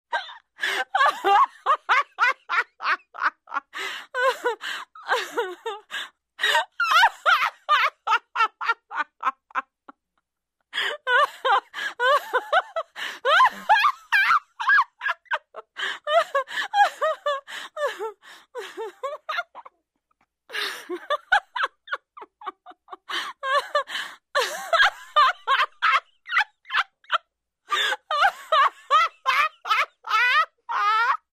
На этой странице собраны разнообразные звуки смеха девочек разного возраста — от детского звонкого смешка до эмоционального подросткового хохота.
Заливается смехом